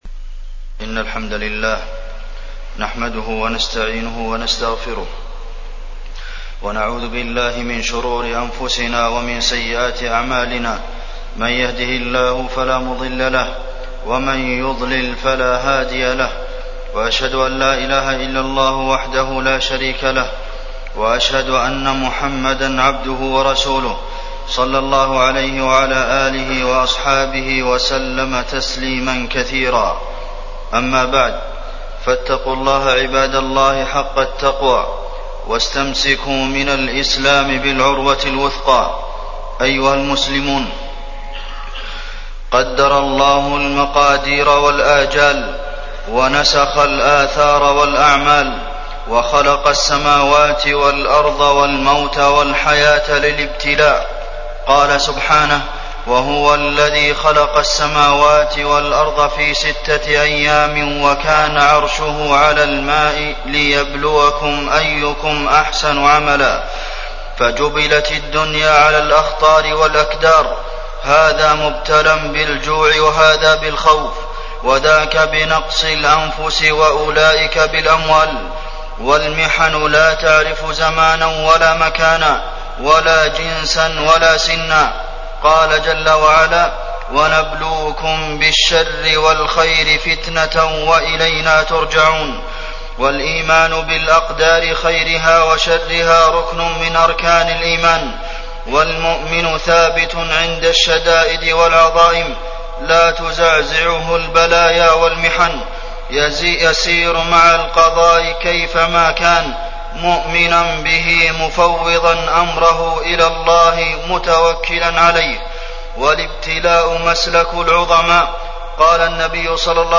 تاريخ النشر ٢٦ محرم ١٤٣٠ هـ المكان: المسجد النبوي الشيخ: فضيلة الشيخ د. عبدالمحسن بن محمد القاسم فضيلة الشيخ د. عبدالمحسن بن محمد القاسم الابتلاء